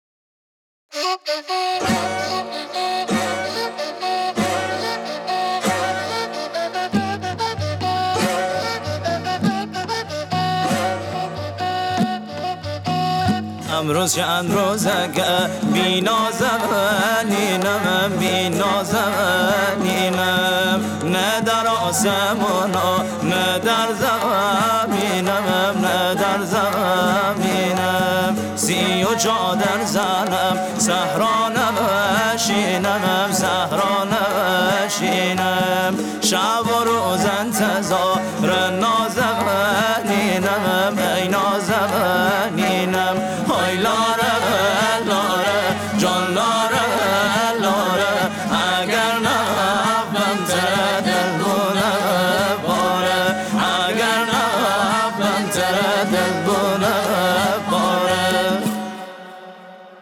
اجرای زنده موسیقی محلی
تاریخ اجرا: 22 و 23 آذر 1403 - مکان اجرا: بابلسر، سالن اداره ارشاد بابلسر - خرید بلیت اینترنتی